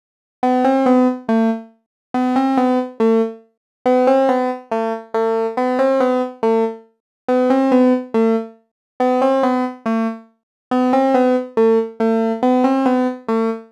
Exodus - Soft E-Piano.wav